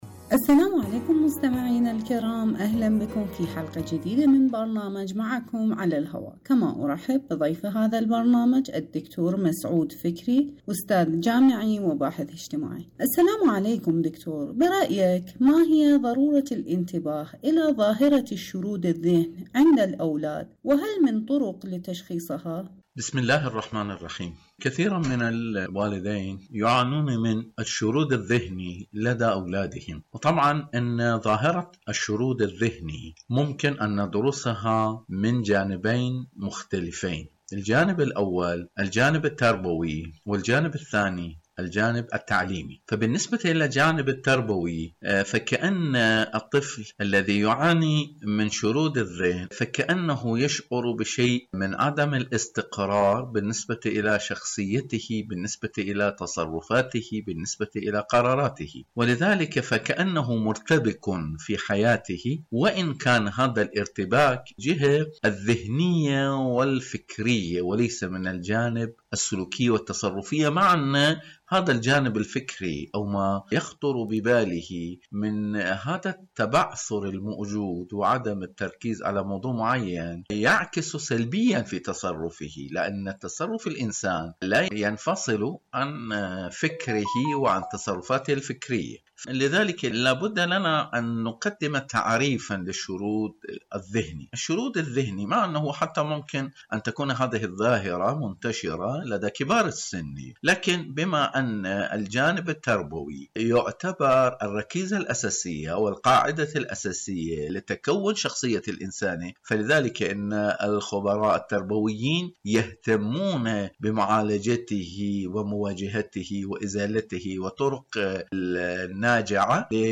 ولدي شارد الذهن، ماذا أفعل؟.. مقابلة